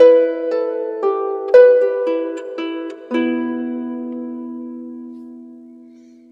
Harp05_114_G.wav